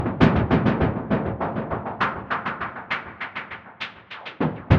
Original creative-commons licensed sounds for DJ's and music producers, recorded with high quality studio microphones.
True Piano melody.wav
TruePianos_2__oMd.wav